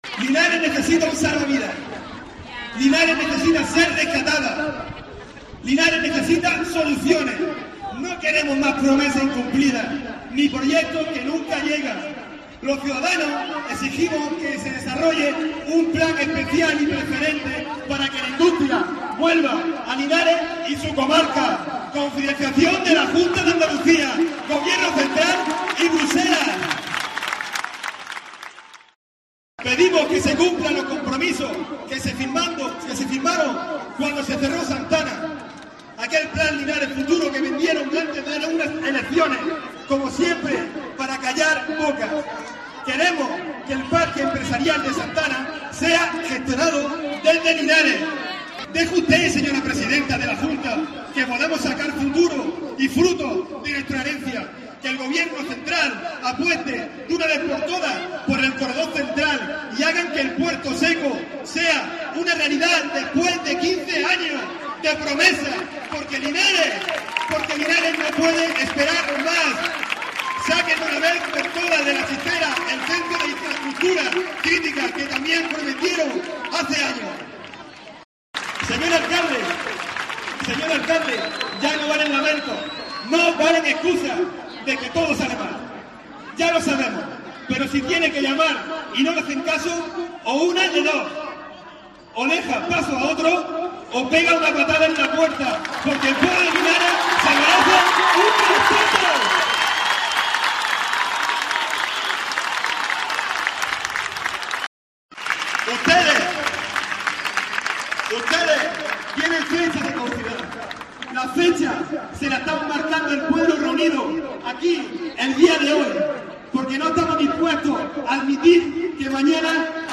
Fragmento del manifiesto de la Plataforma "Todos a una por Linares" leido
finalizaba en la plaza del Ayuntamiento de Linares